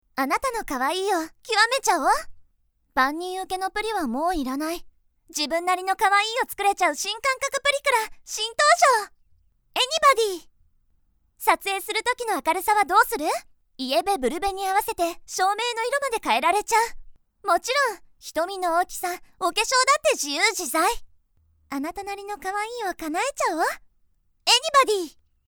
I am capable of bright and energetic narration, but I am also good at expressing things in depth.
– Narration –
Feminine